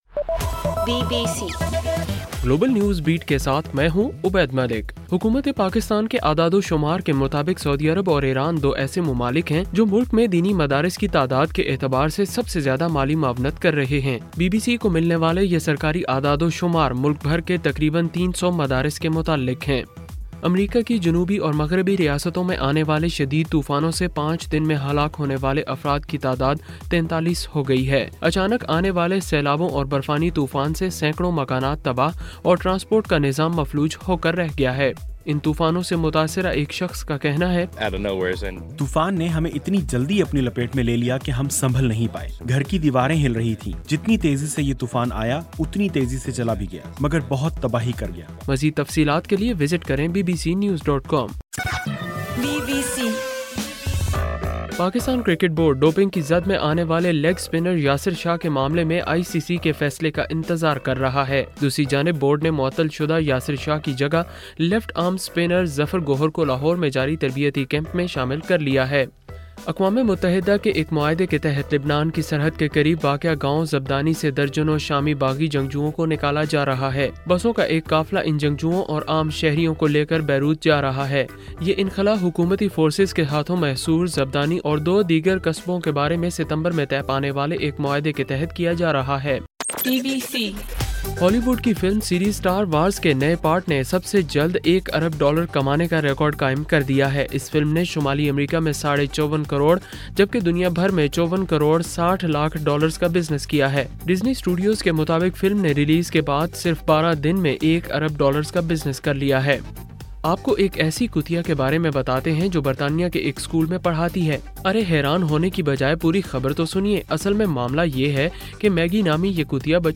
دسمبر 28: رات 12 بجے کا گلوبل نیوز بیٹ بُلیٹن